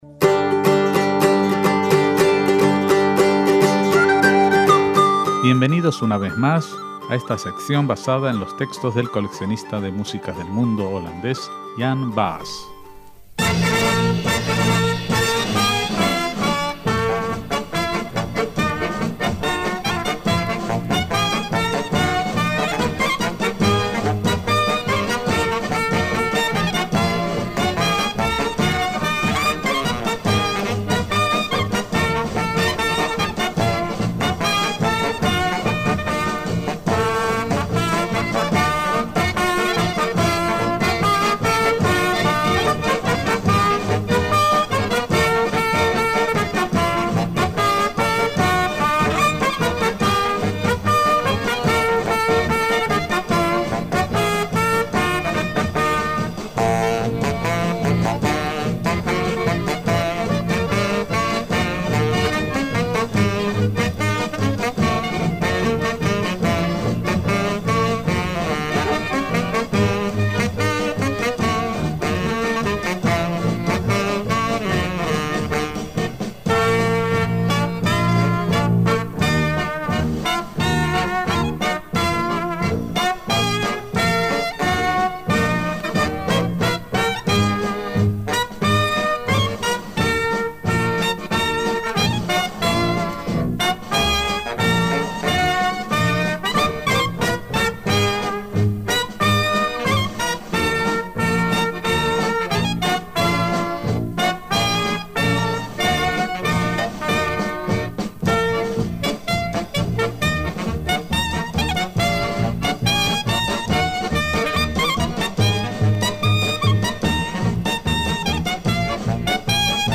Y si hay una oportunidad que los judíos hemos aprovechado para nuestras músicas más alegres son las bodas.